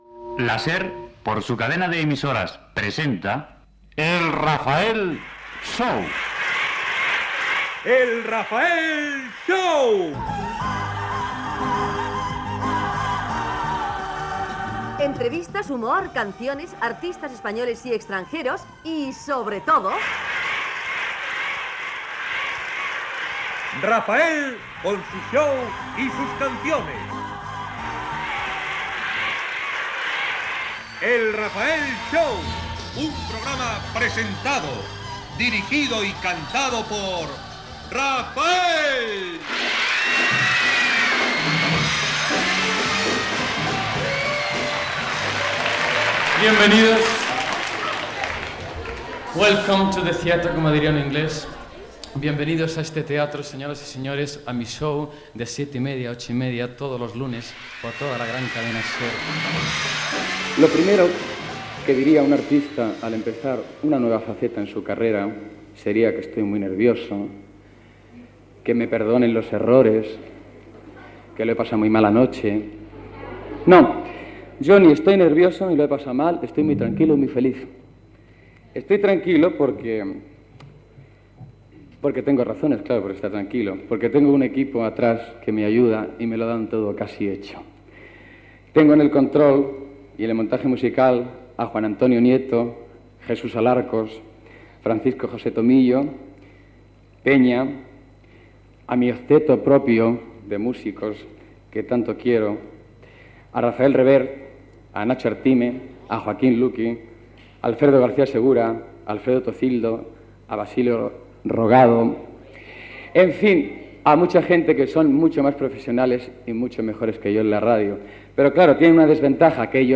Careta del programa, benvinguda, equip del programa i responsables de la Cadena SER que ho han fet possible